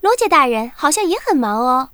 文件 文件历史 文件用途 全域文件用途 Daphne_amb_02.ogg （Ogg Vorbis声音文件，长度0.0秒，0 bps，文件大小：27 KB） 源地址:游戏语音 文件历史 点击某个日期/时间查看对应时刻的文件。